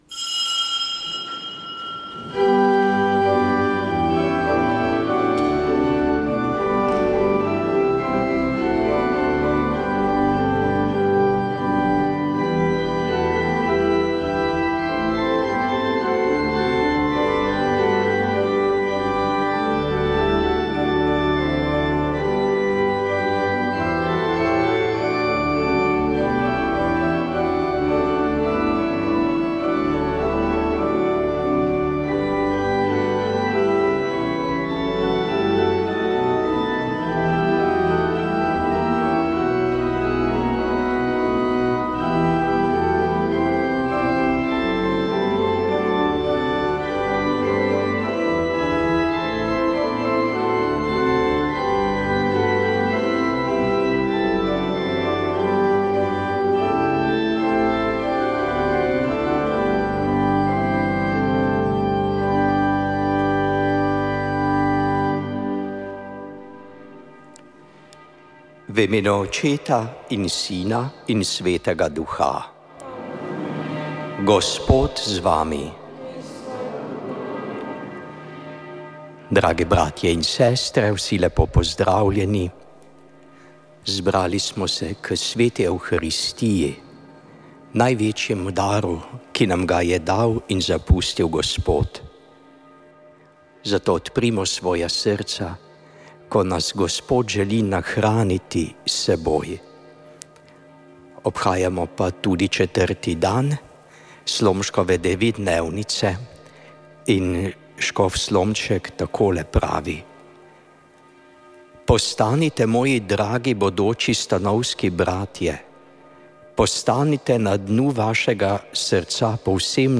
Sveta maša
Sveta maša za domovino iz stolnice svetega Nikolaja v Ljubljani
Maševanje je vodil nadškof metropolit Stanislav Zore.